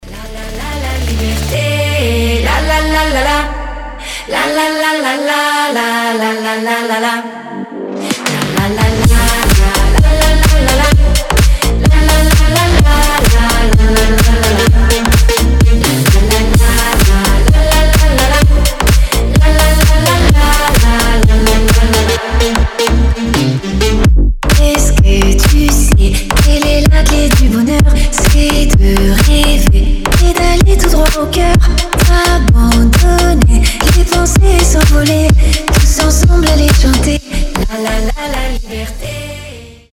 • Качество: 320, Stereo
громкие
мощные
EDM
future house
басы
энергичные
slap house